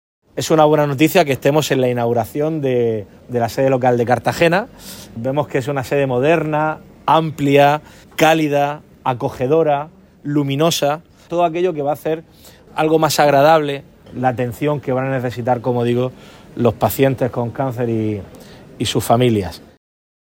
Declaraciones del presidente López Miras durante la inauguración de la nueva sede en Cartagena de la Asociación Española contra el Cáncer